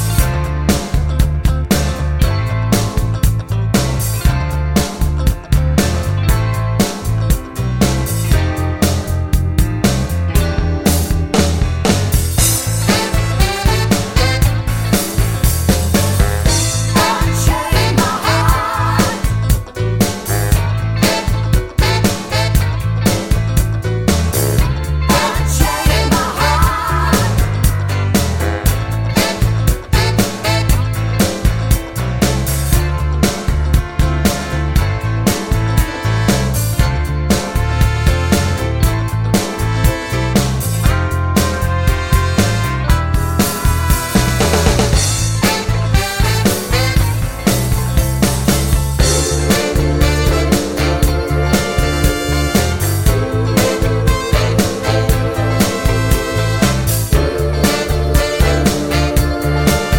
no Backing Vocals Soft Rock 5:03 Buy £1.50